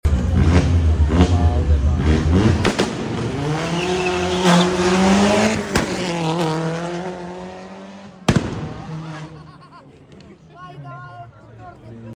Antilag launch control + no lift shift 🔥 1.8T 20v Stage 2 230Hp 360Nm